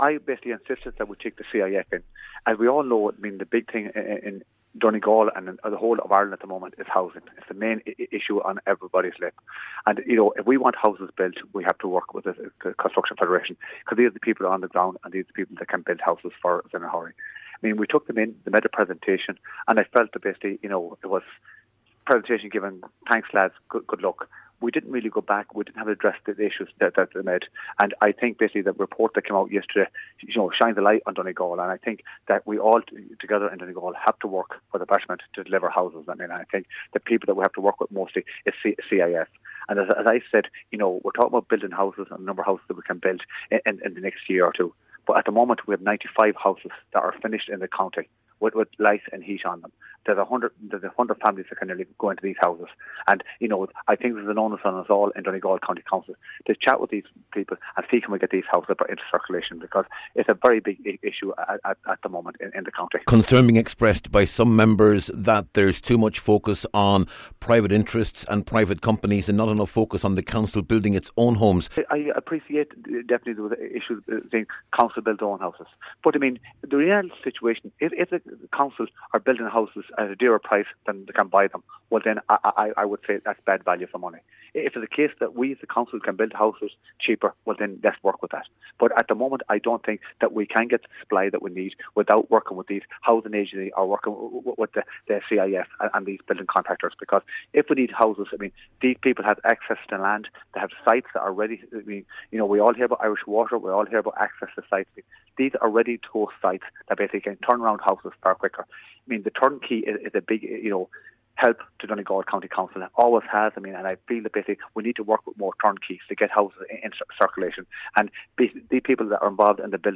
There were clashes at a special housing meeting at County House in Lifford this week between the Cathaoirleach of the Donegal Municipal District and a West Donegal independent councillor.